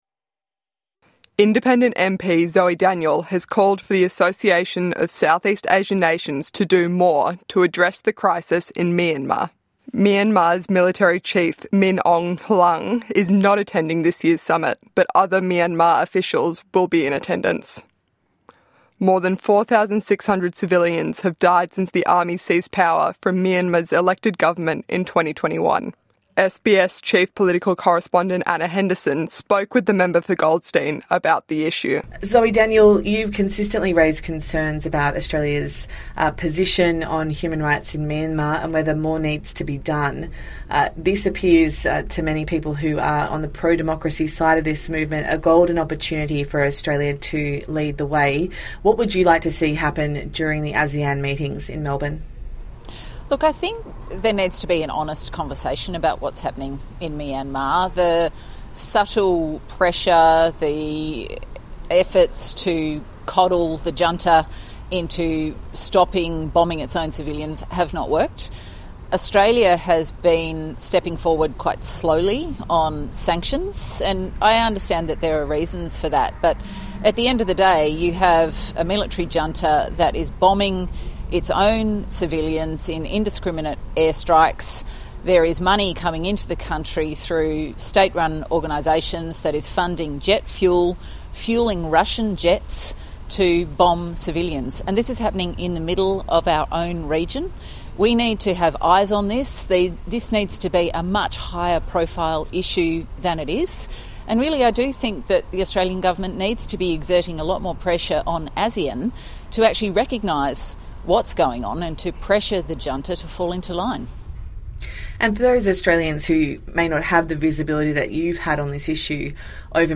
INTERVIEW: MP Zoe Daniel calls for ASEAN to act on Myanmar rights crisis